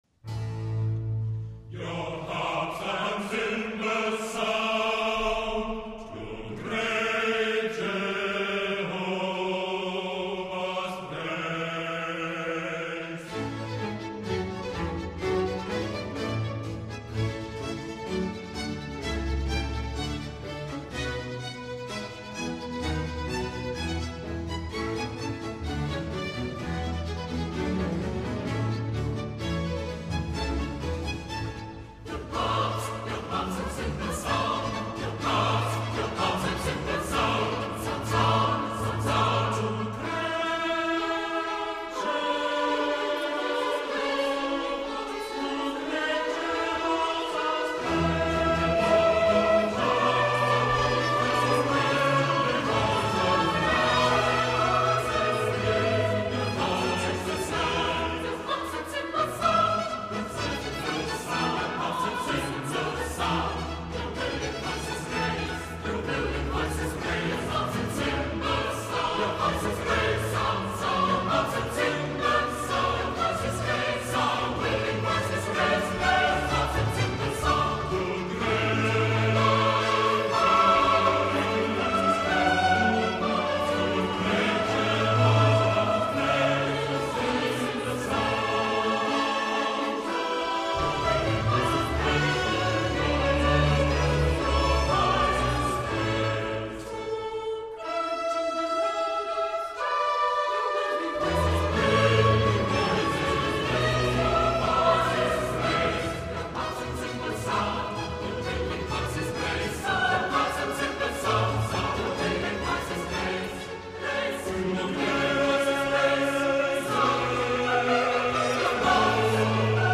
Er legt vor allem Wert darauf, so viel wie möglich von der ganz besonderen Aura der Konzerte zu vermitteln, was ausgezeichnet gelingt.
Chorus from Joshua ~ CD II, No. 18